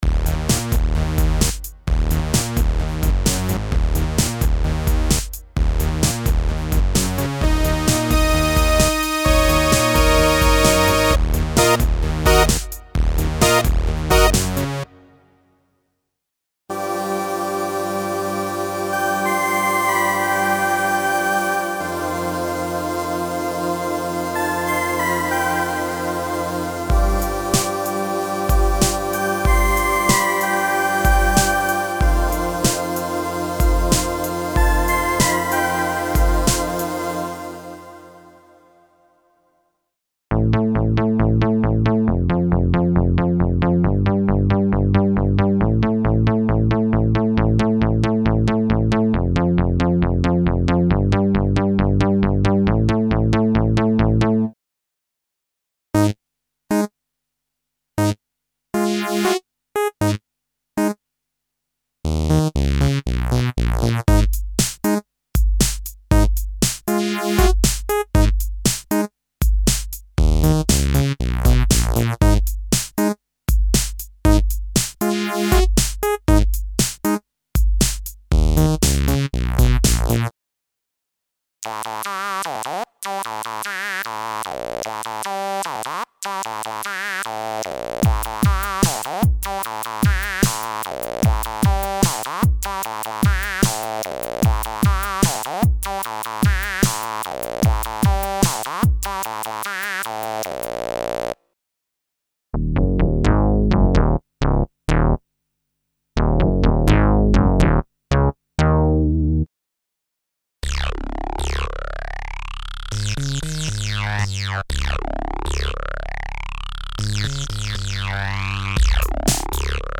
Emulations of vintage analog synthesizers (synth basses - including the special "Minimoog" bass variations, mono leads, poly synths and seq. programs).
Info: All original K:Works sound programs use internal Kurzweil K2500 ROM samples exclusively, there are no external samples used.